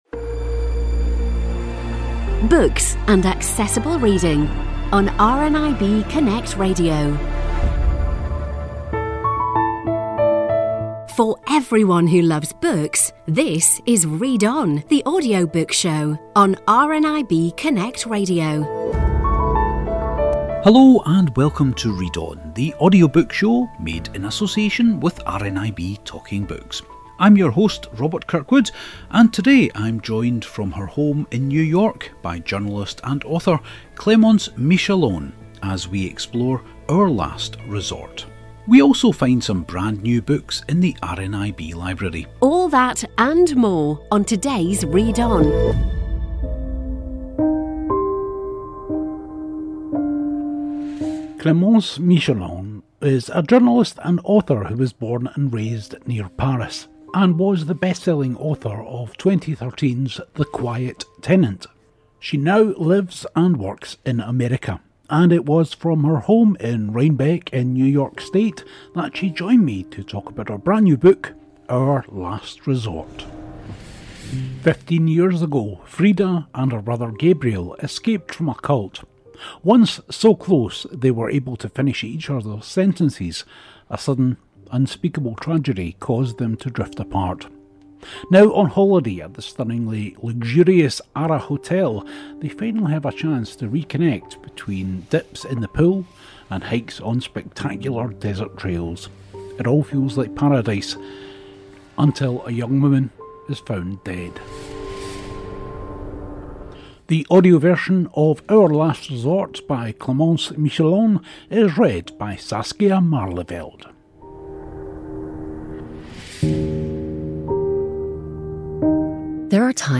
from her home in New York